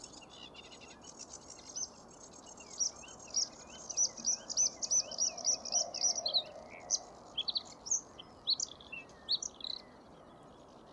نام انگلیسی :Blue Throat
نام علمی :Luscinia svecica